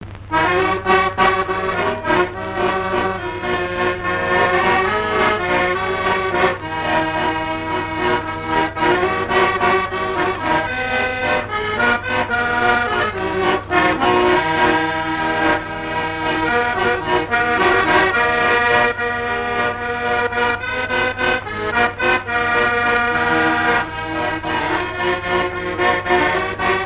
danse : valse
Pièce musicale inédite